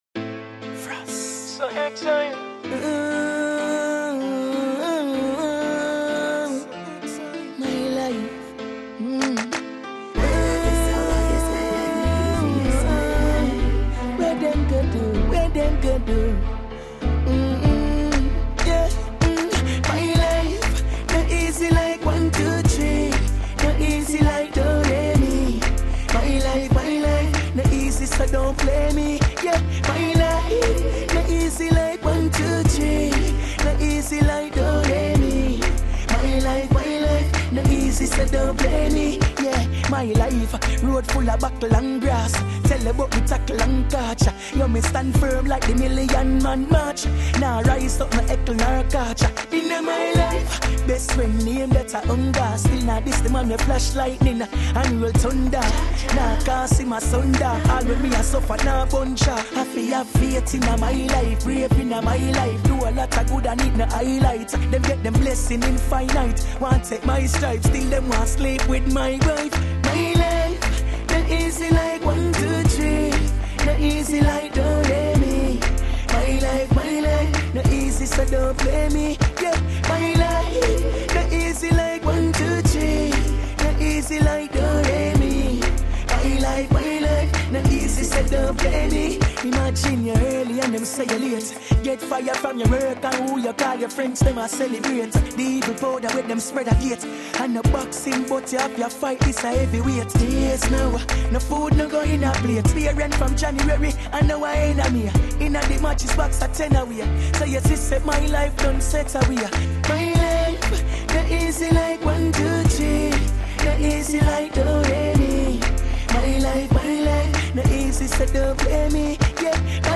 Jamaican reggae